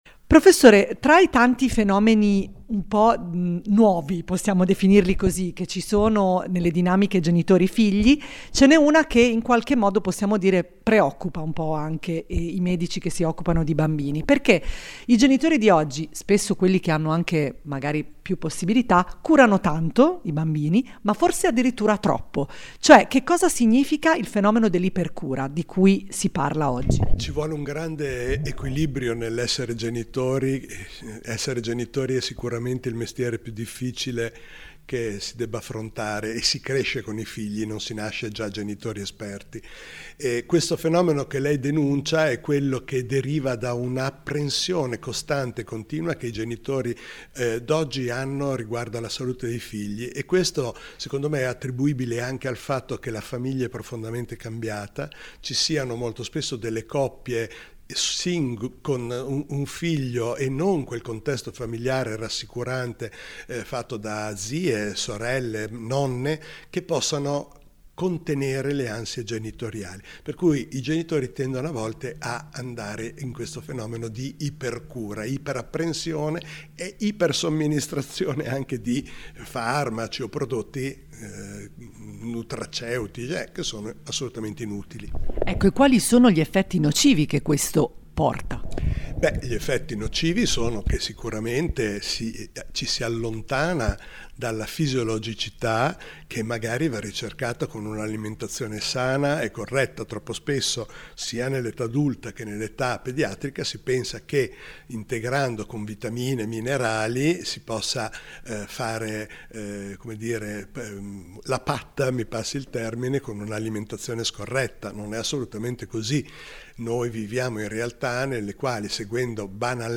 Home Magazine Interviste Il fenomeno dell’ipercura